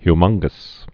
(hy-mŭnggəs)